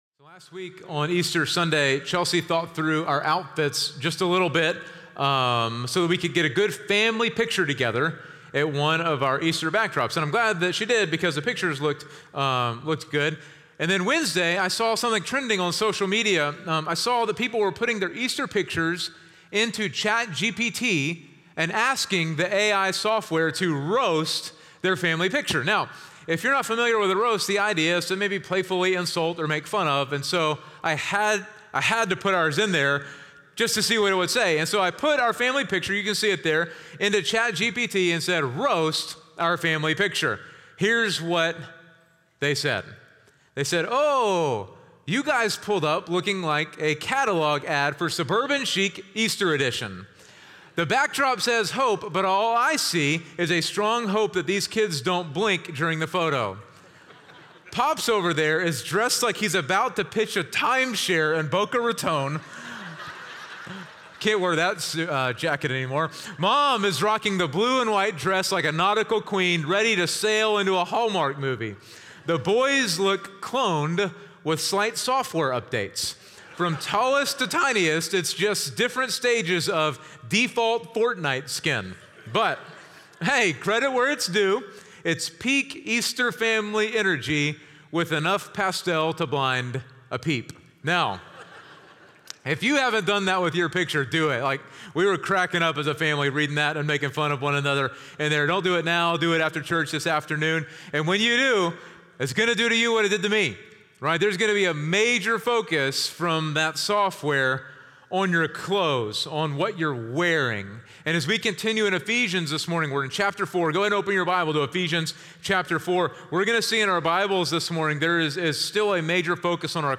North Klein Sermons – Media Player